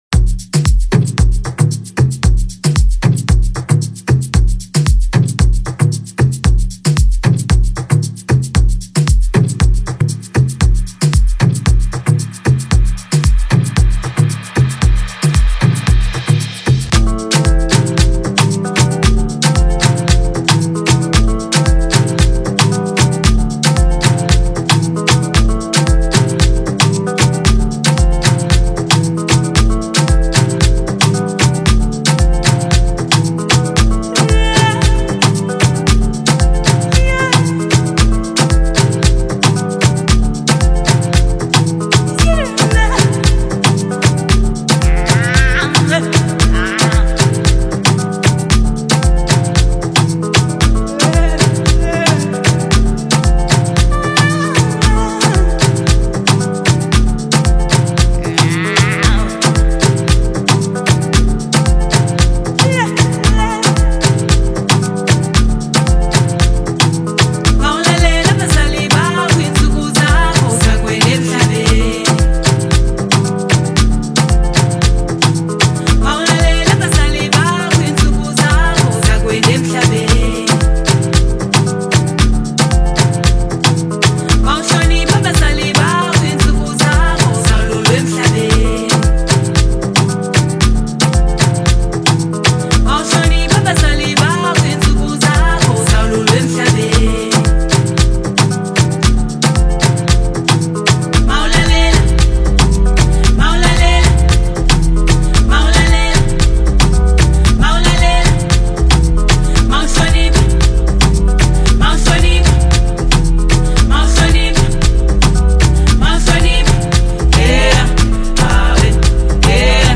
beautifully sung on an Amapiano instrumental